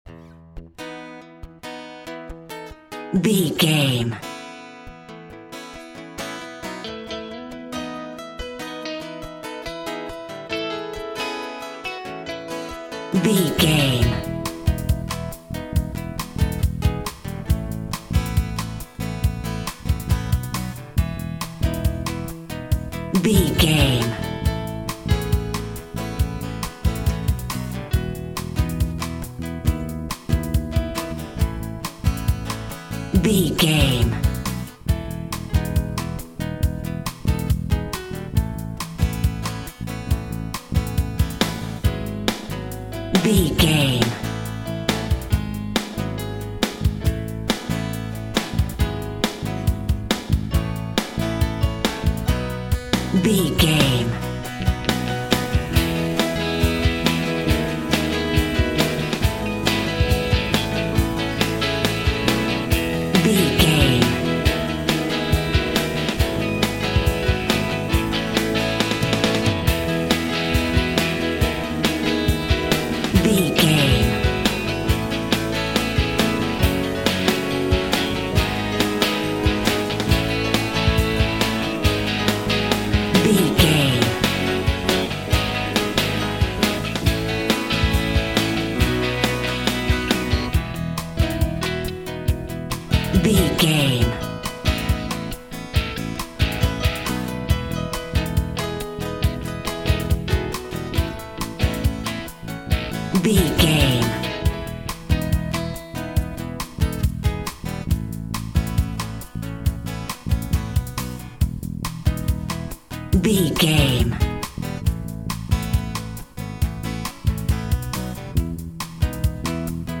Love Ballad Uptempo Theme.
Ionian/Major
pop
cheesy
pop rock
synth pop
drums
bass guitar
electric guitar
piano
hammond organ